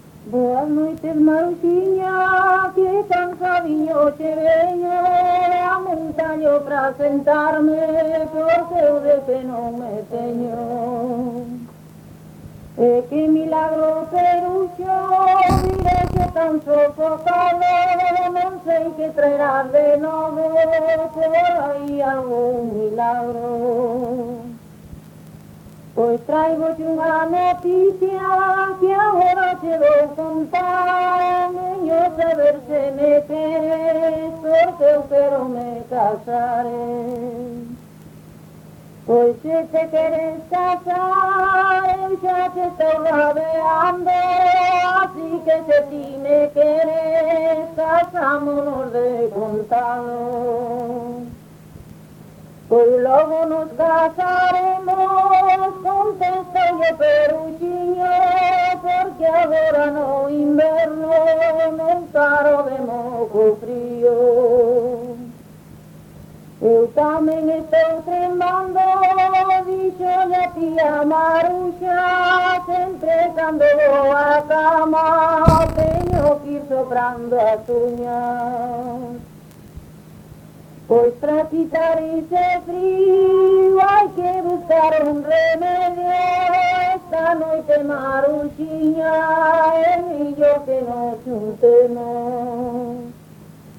Tipo de rexistro: Musical
Áreas de coñecemento: LITERATURA E DITOS POPULARES > Cantos narrativos
Lugar de compilación: Chantada - A Grade (San Vicente) - Quintá
Soporte orixinal: Casete
Instrumentación: Voz
Instrumentos: Voz feminina